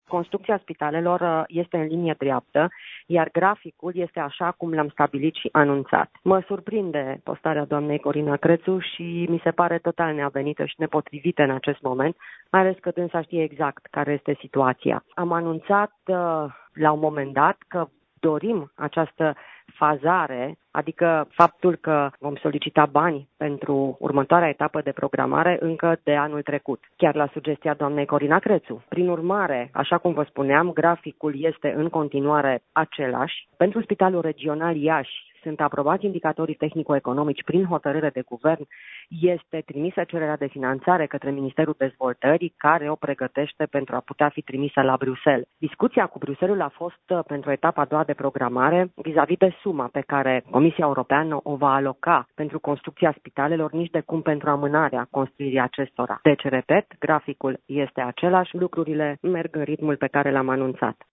Ministrul sănătăţii, Sorina Pintea, a declarat, astăzi, pentru postul nostru de radio, că graficul privind construcţia celor trei spitale regionale de urgenţă, de la Iaşi, Cluj Napoca şi Craiova, rămâne cel stabilit şi anunţat.